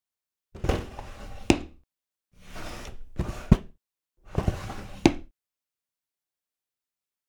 household
Cabinet Door Slides Close Open